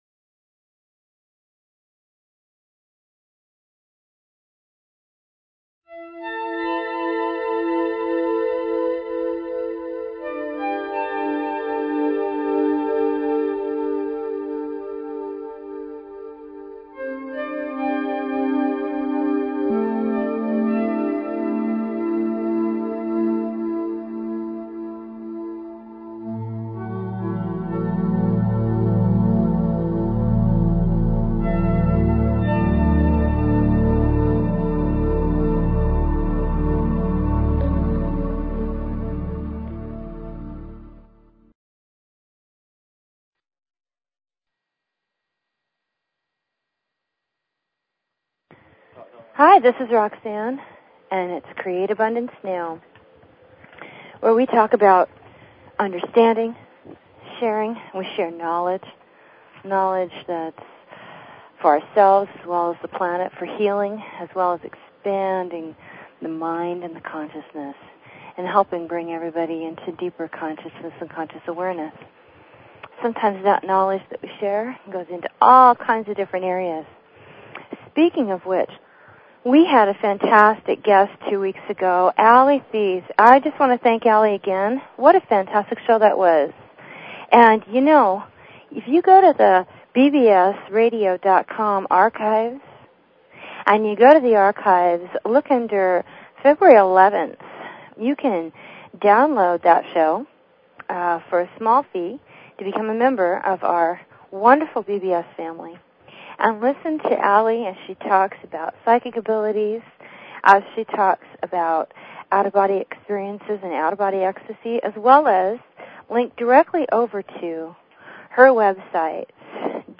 Talk Show Episode, Audio Podcast, Create_Abundance_Now and Courtesy of BBS Radio on , show guests , about , categorized as